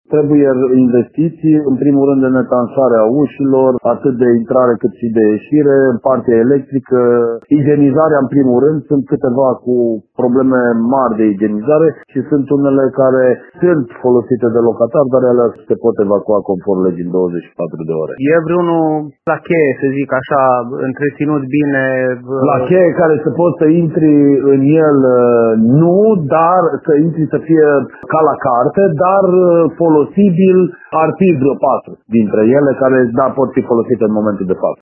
Viceprimarul Timișoarei, Cosmin Tabără, precizează însă că cele mai multe dintre adăposturile de protecție civilă au nevoie de investiții.